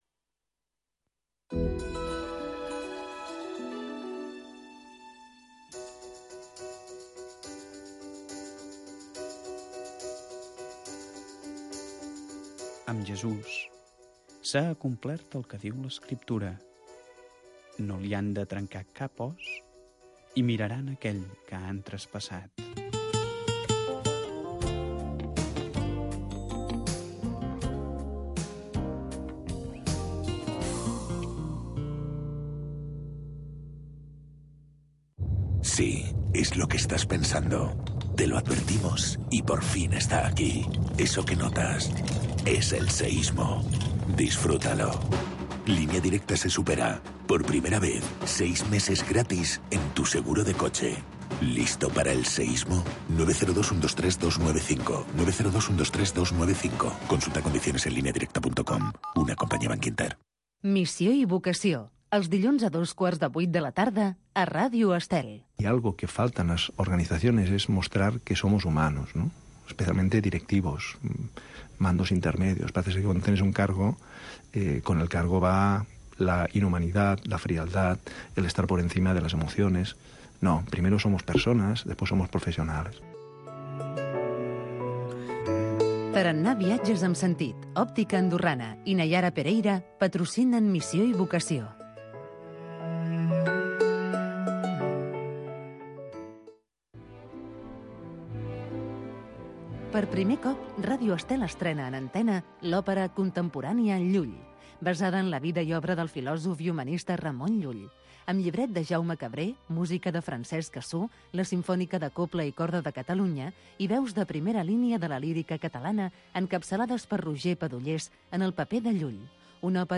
Església viva. Magazine d’actualitat cristiana del bisbat de Girona.